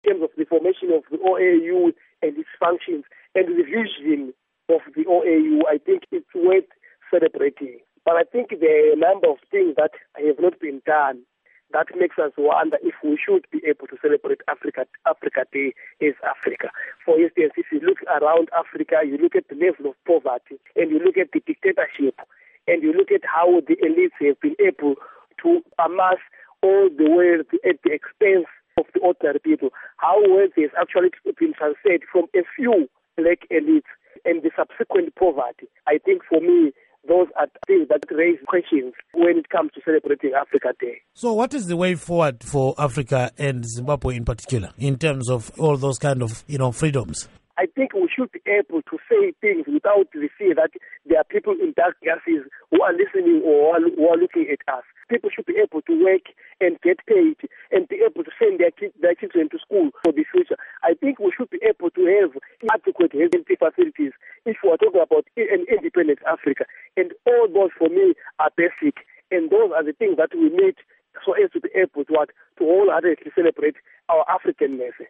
Interview With Rugare Gumbo